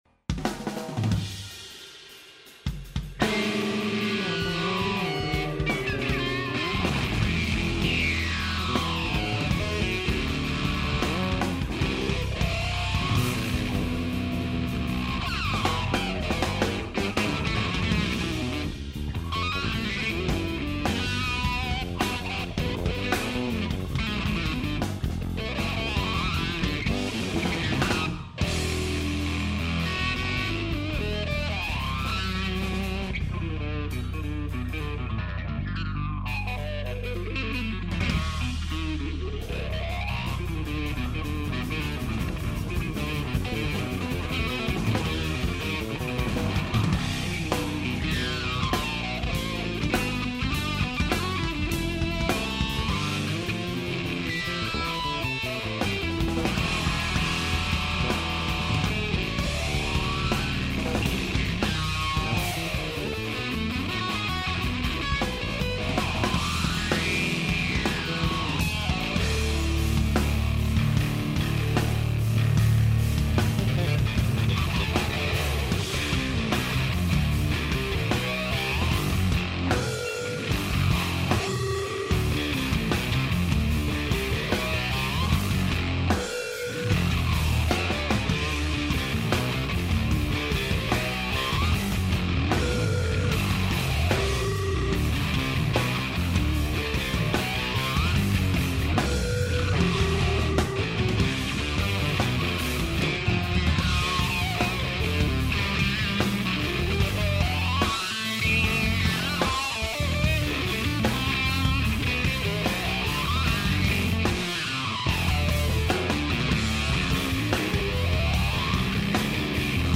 at The Baked Potato, North Hollywood, CA
Rock swing at warp speed
baritone guitar